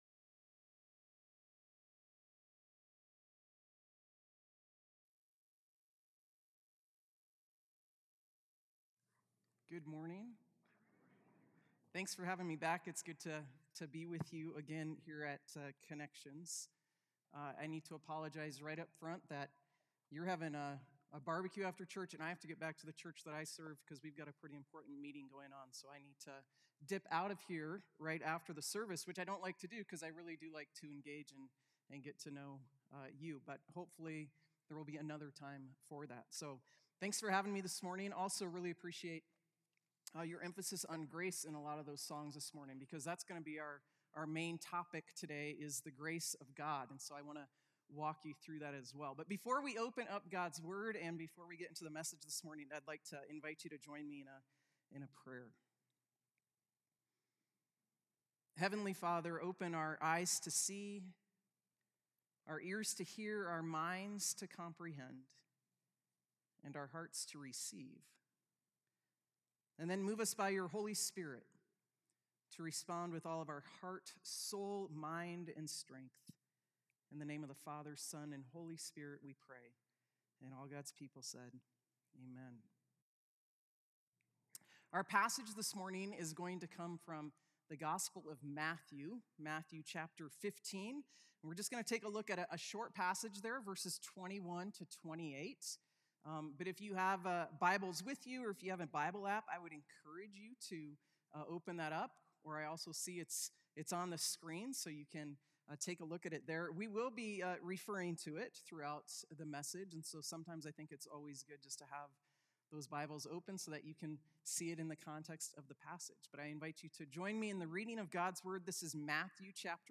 A message from the series "Sunday Services."